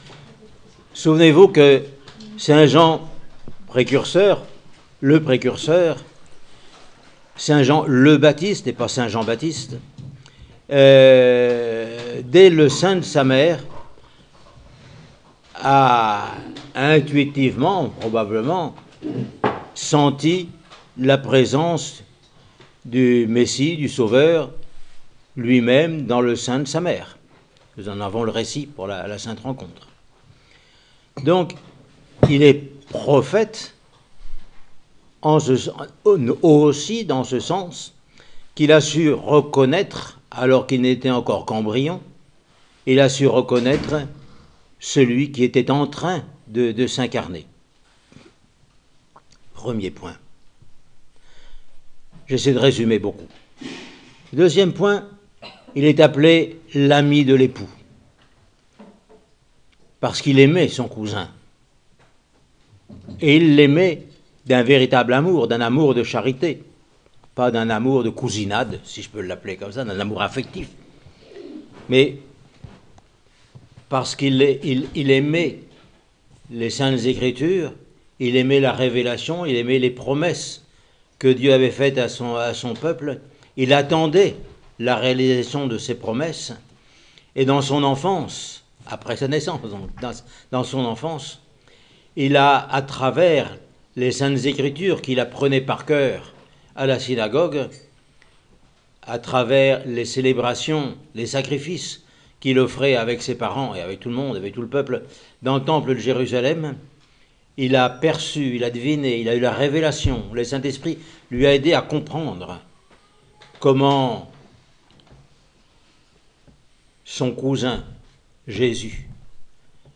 Homélie sur Saint Jean le Précurseur :Monastère de la Transfiguration